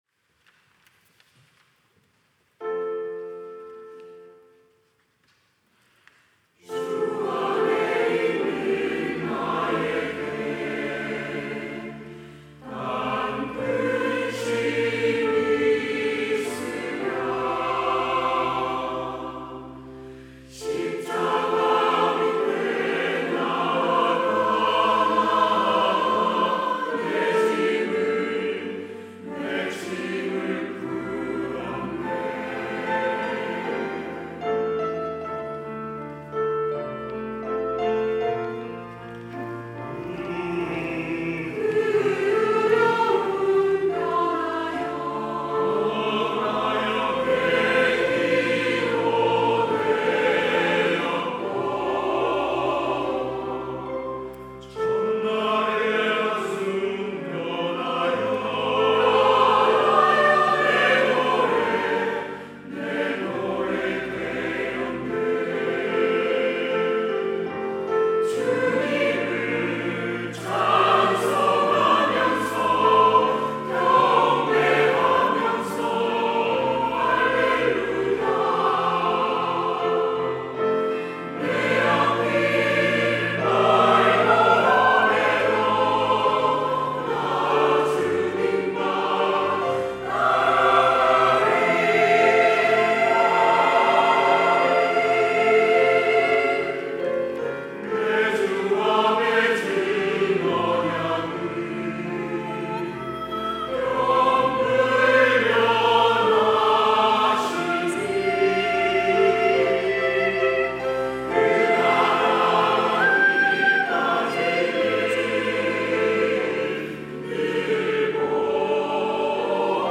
찬양대 호산나